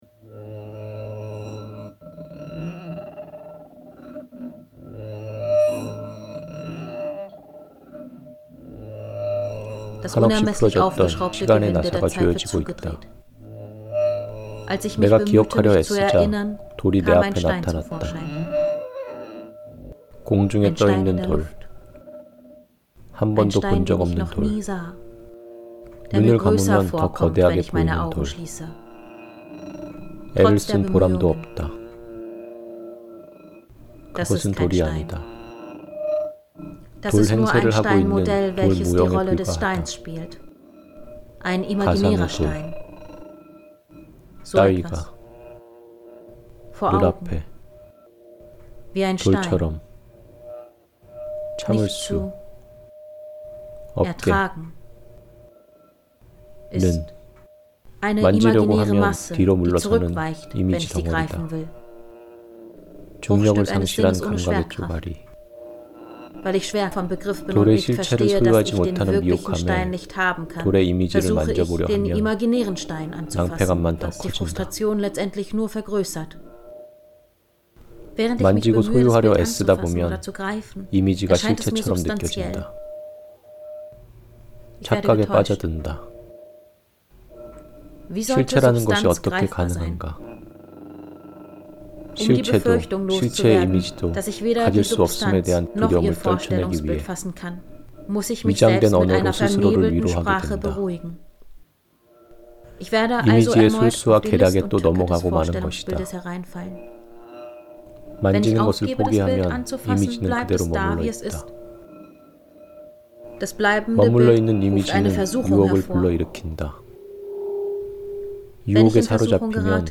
오디오극 <제0장: 뜻밖의 모든 것>은 한국어와 독일어가 동시에 사용되는 오디오극입니다. 주로 왼쪽으로는 한국어, 오른쪽으로는 독일어가 들릴 것입니다. 그밖에 필드레코딩 사운드가 함께하기도 합니다. 소리의 방향성이 중요하기 때문에 반드시 헤드폰을 착용하고 들으시길 권합니다.